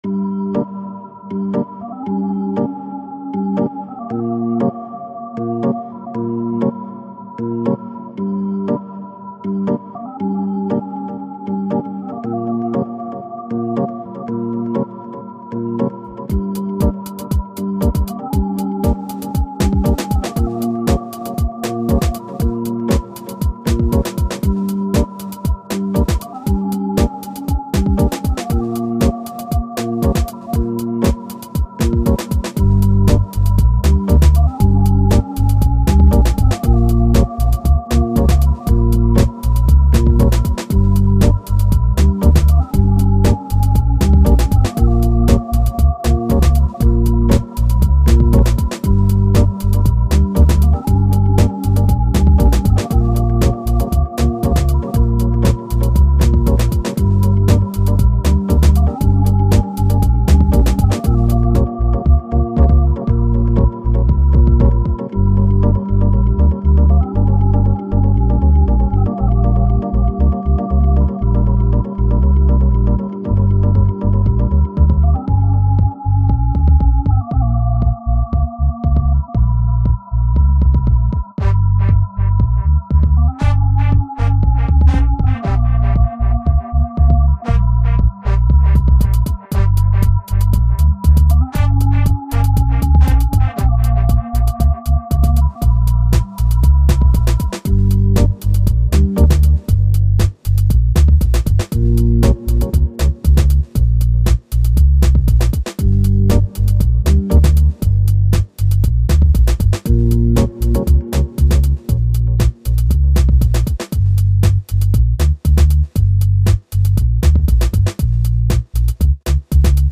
Dub made with Reason & Consciousness !